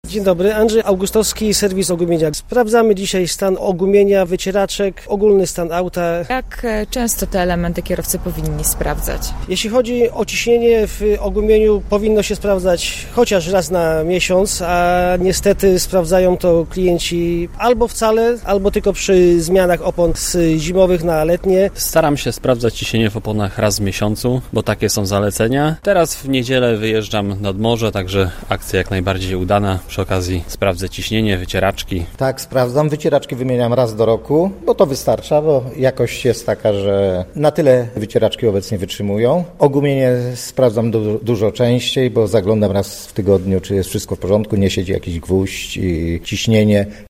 Kontrolę auta można przeprowadzać do godziny 16.00, w serwisie ogumienia w Zielonej Górze przy ul. Wyspiańskiego. Pierwsi kierowcy już z samego rana sprawdzali swoje pojazdy: